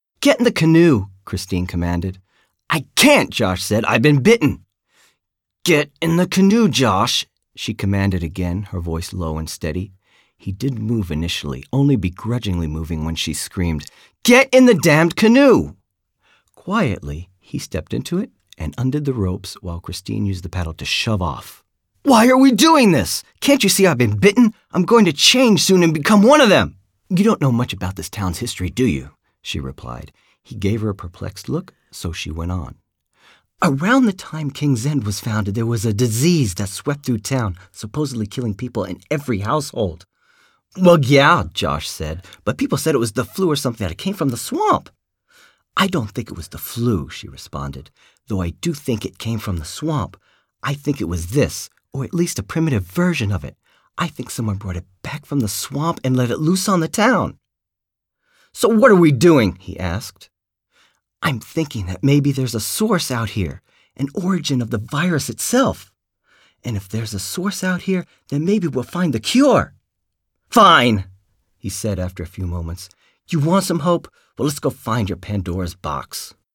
Free Audio-Story Demo!